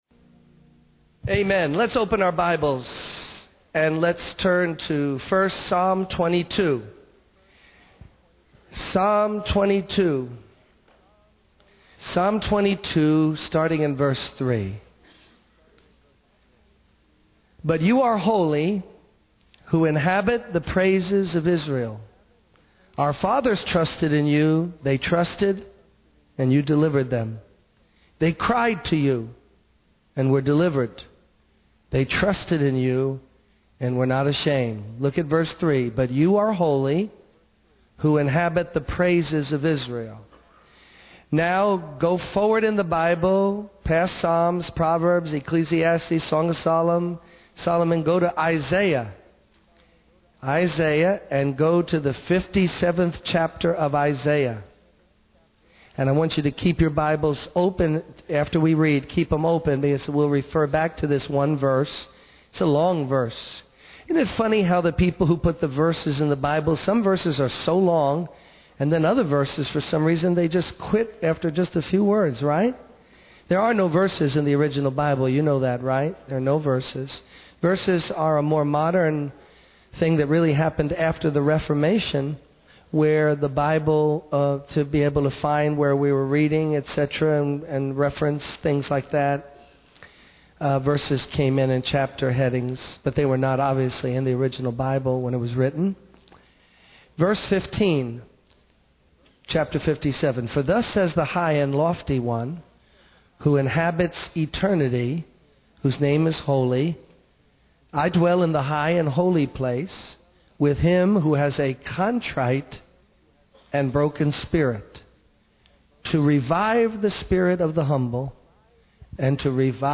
In this sermon, the speaker emphasizes the importance of setting our minds, hearts, and affections on eternal things where God dwells. They highlight the contrast between the value of earthly things and the value of heavenly things.